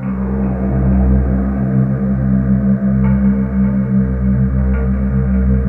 Index of /90_sSampleCDs/USB Soundscan vol.28 - Choir Acoustic & Synth [AKAI] 1CD/Partition C/12-LIVES